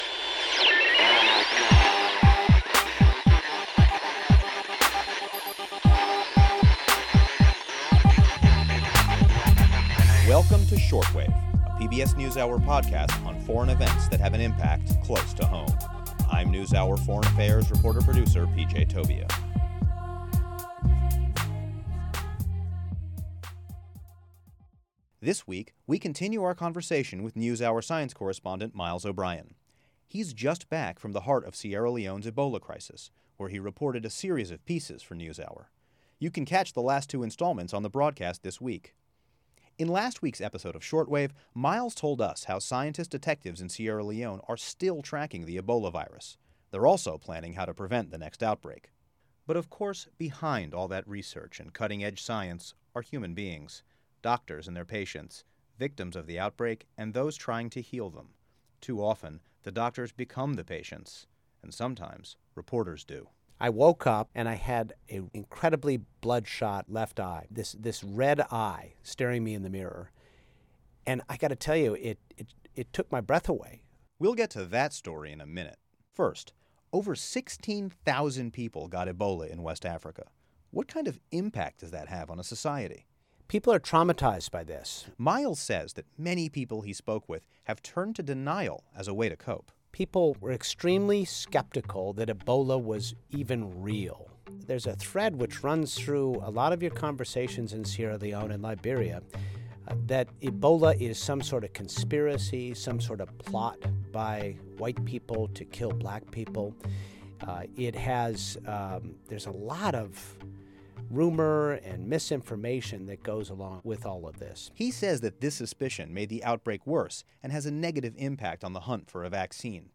In this week's Shortwave, we hear the voice of one of these health-workers who lost some of her closest colleagues to the disease they were fighting. Miles also shares his own close call with the virus, and tells us what it was like to wake up the day after his trip to West Africa with one, very scary symptom of the Ebola virus.